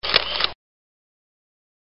Camera Capture Sound.wav